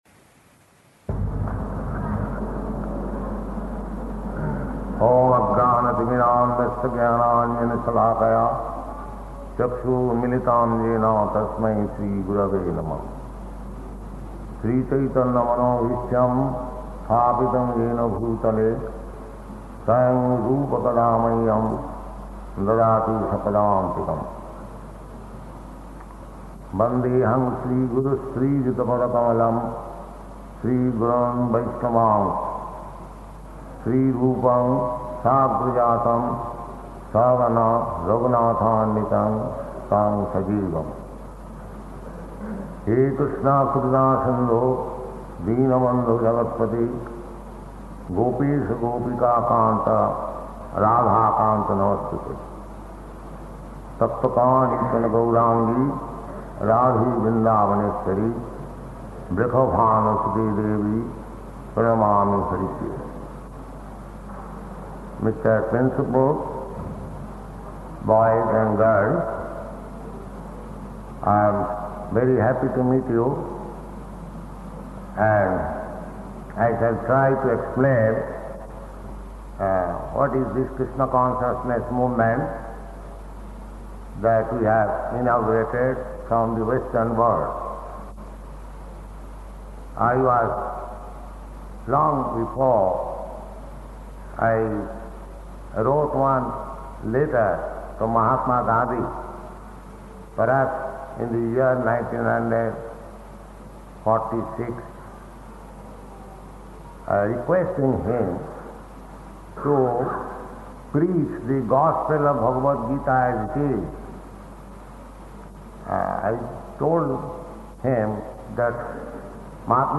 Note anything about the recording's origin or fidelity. Location: Surat